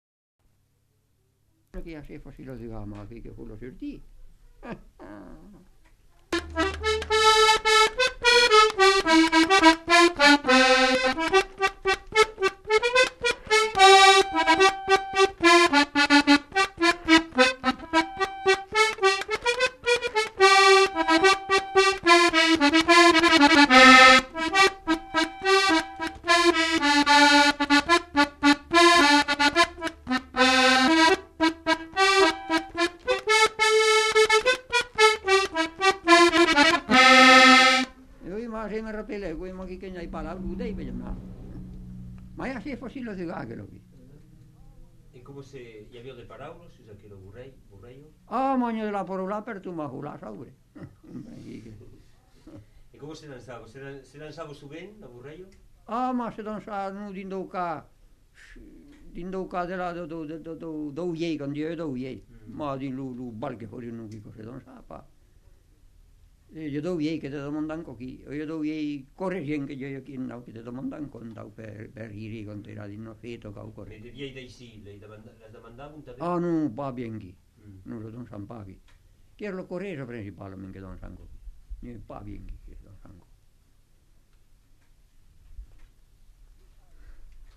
Bourrée
Genre : morceau instrumental
Instrument de musique : accordéon diatonique
Danse : bourrée
Ecouter-voir : archives sonores en ligne